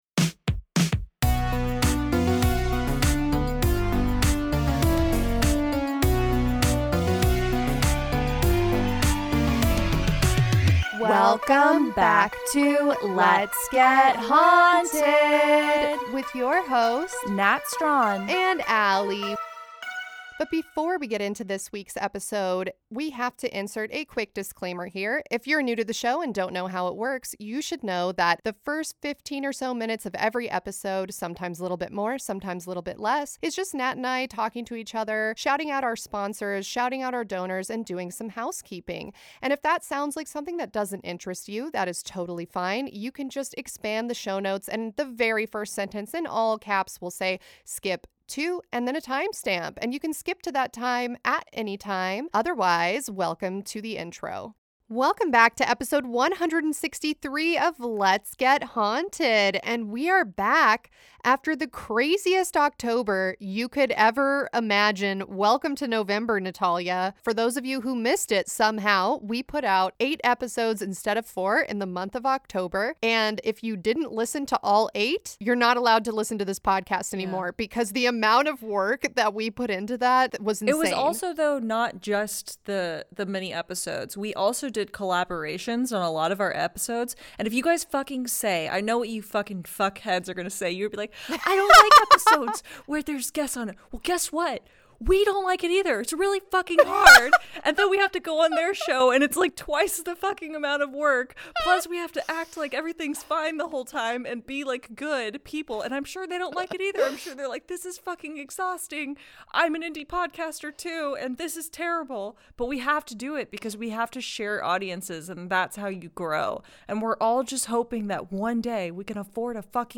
This was supposed to be the intro for Episode 163 but it's too long, too unfiltered, and too off-topic due to sleep deprivation so enjoy this Patreon-only exclusive!